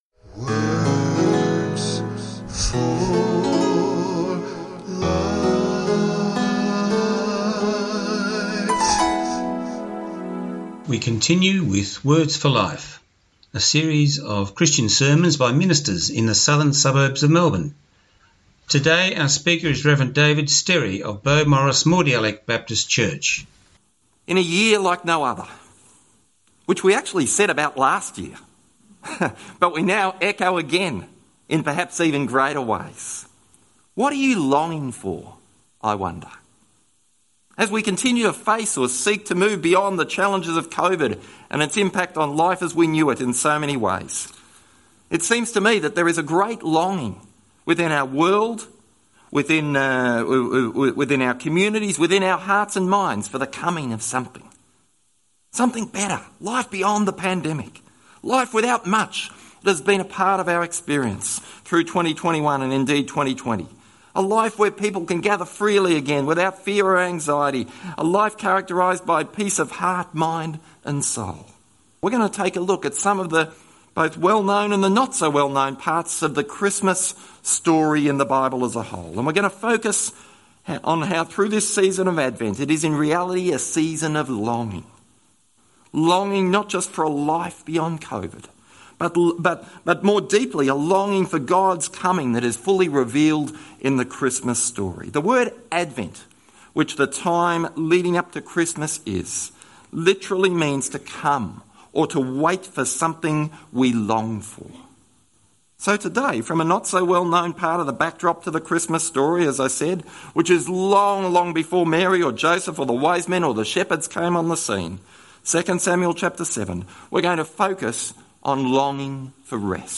Talk time is 15 minutes.